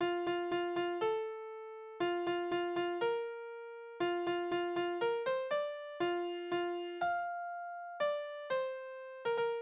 Kniereiterlieder: Hopp, hopp nach dem Müller
Tonart: F-Dur
Taktart: 4/4
Tonumfang: Oktave